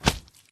Windows64Media / Sound / Minecraft / mob / magmacube / big2.ogg